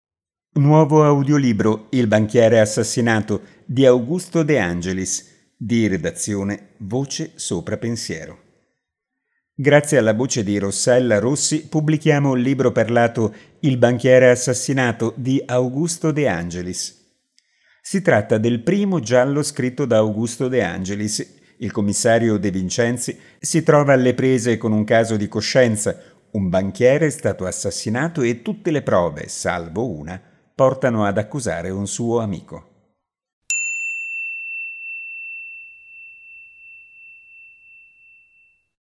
Ascolta gratis l’audiolibro: Il banchiere assassinato di Augusto De Angelis (è disponibile anche la versione ebook).